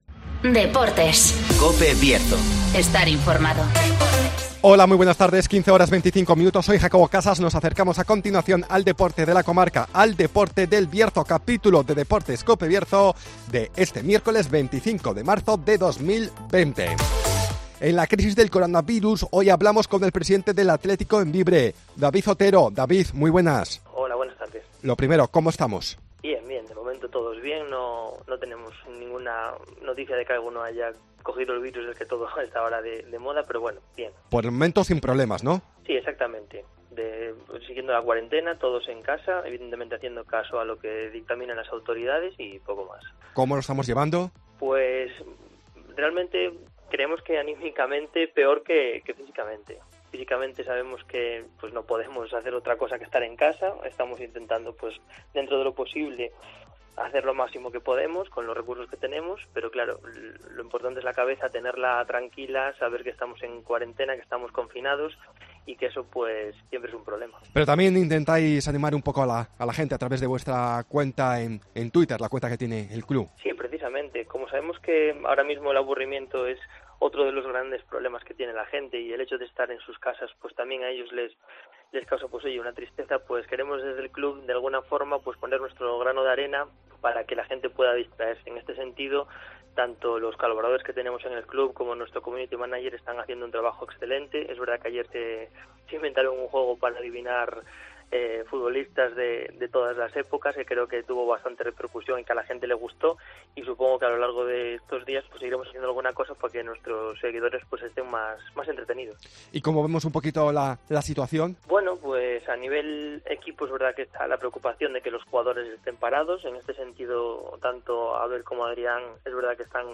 -Crisis del coronavirus -Entrevista